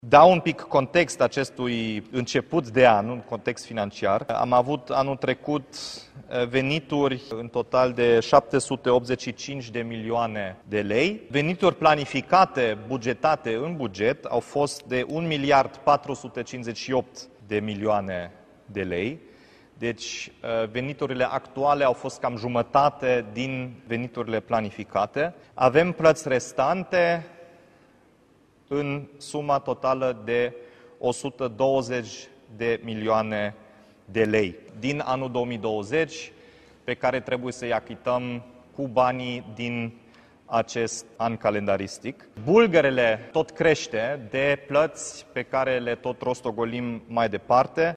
Primăria Timișoara începe anul cu datorii de 120 de milioane de lei. Primarul Dominic Fritz, susține că datoriile sunt încă moștenire de la fostul primar, care a estimat la începutul anului 2020 venituri de 1,4 miliarde lei, dar a încasat doar 780 de milioane de lei: